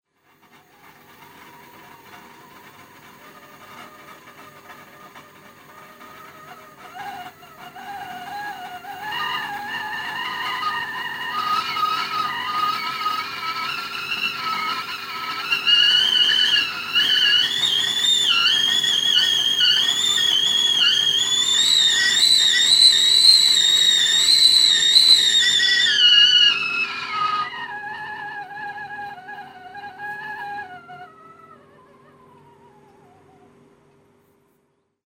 Whistling kettle
Whistling-kettle.mp3